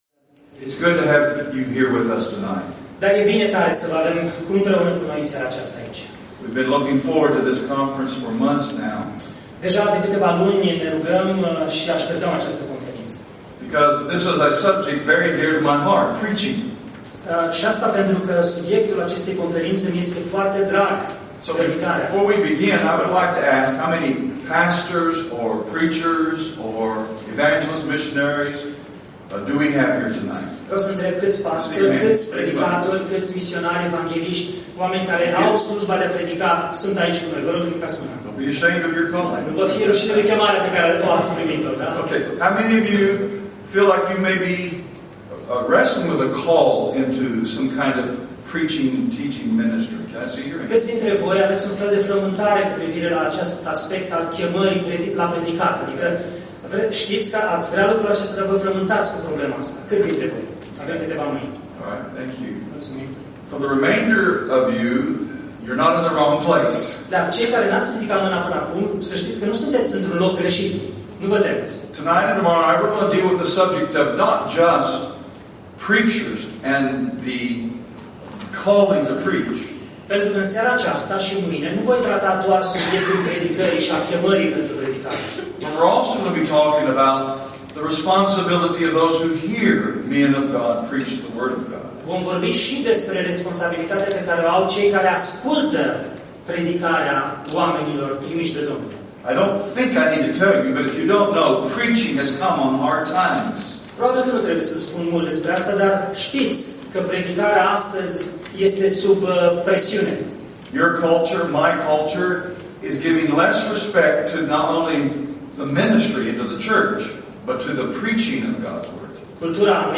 Categorie: Predici Complete